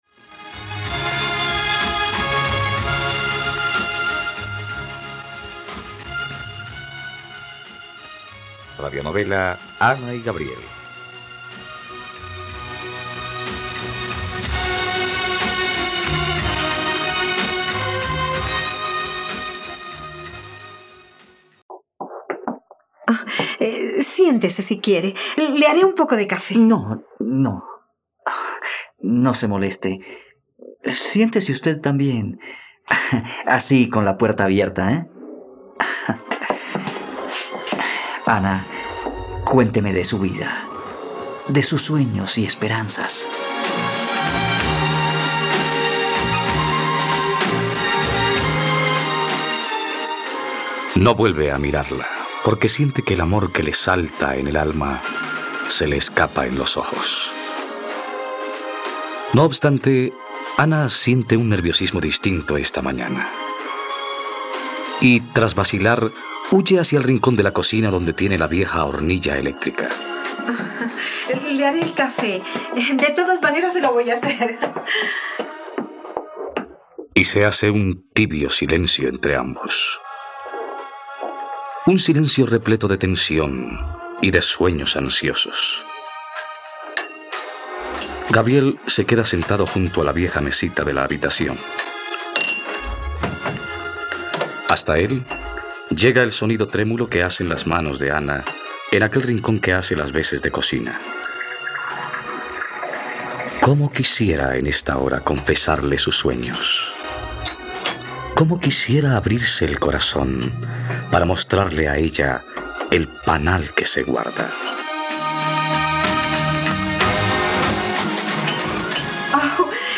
..Radionovela. Escucha ahora el capítulo 31 de la historia de amor de Ana y Gabriel en la plataforma de streaming de los colombianos: RTVCPlay.